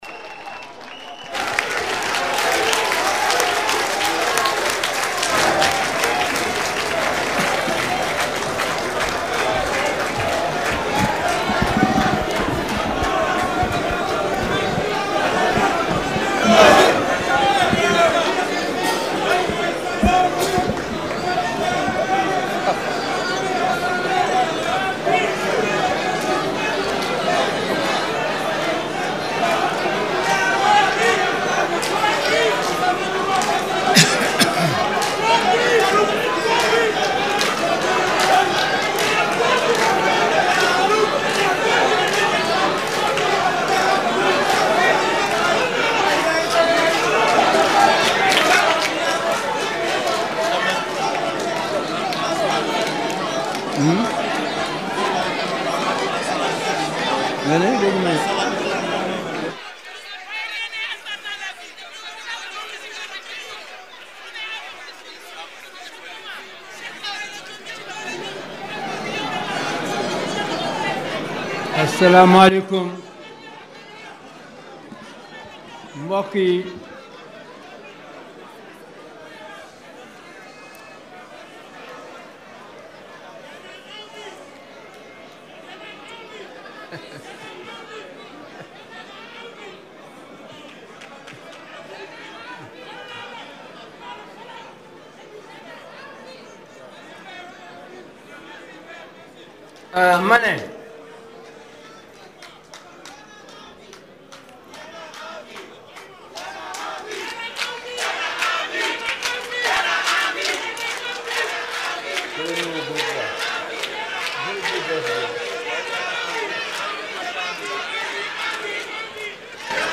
SON Direct hôtel des Almadies: Wade se confesse et menace